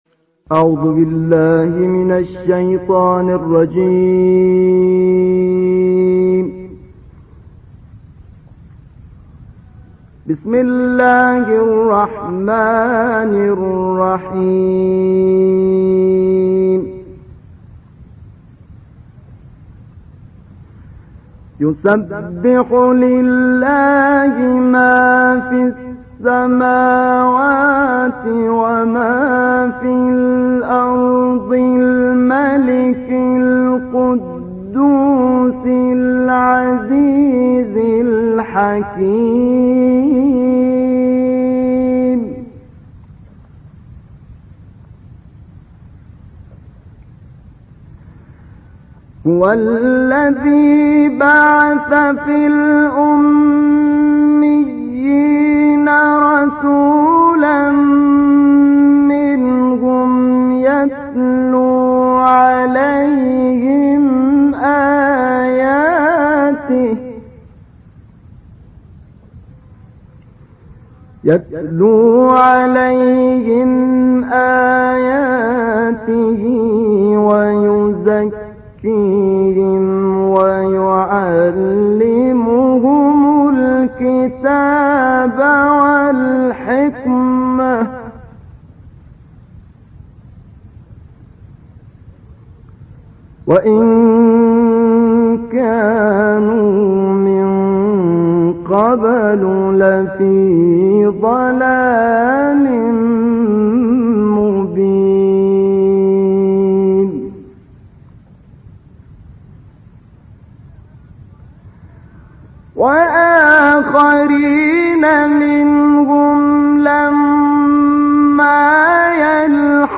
مدت زمان این تلاوت استودیویی 9 دقیقه است.
تلاوت استودیویی